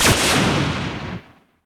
laserstop.ogg